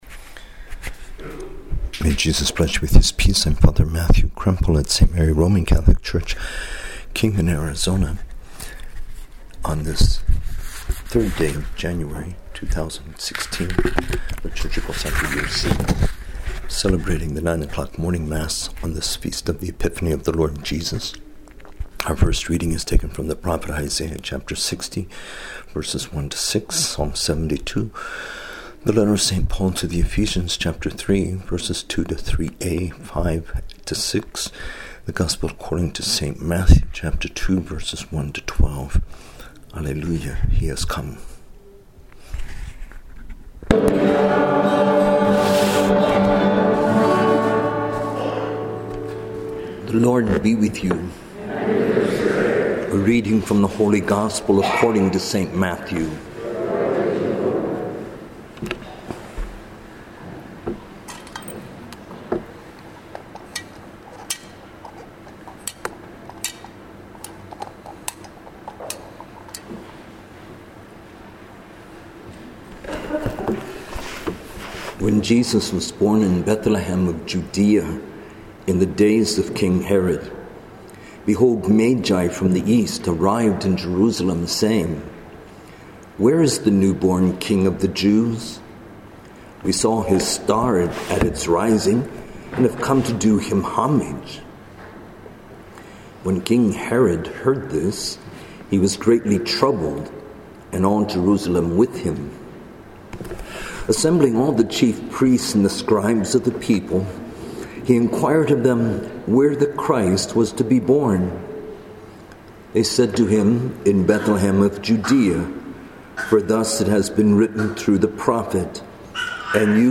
English Homilies